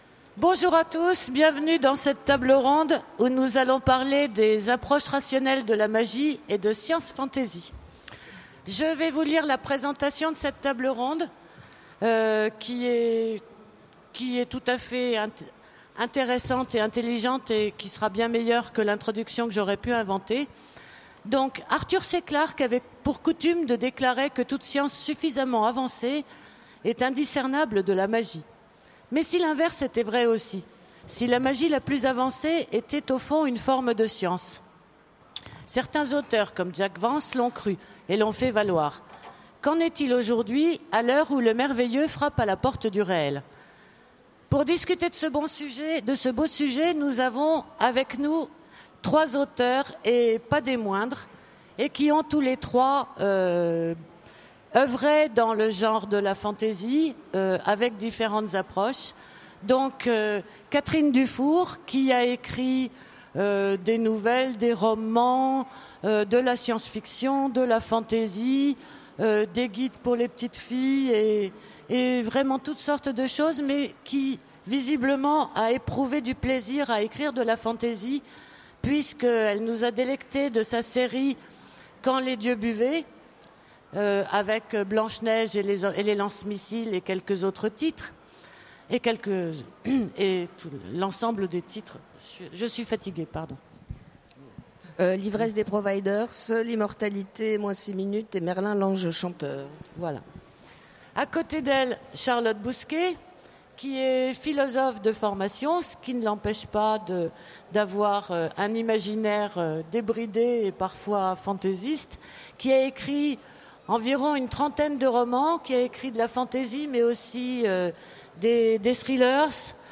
Utopiales 2015 : Conférence Pour une approche rationnelle de la magie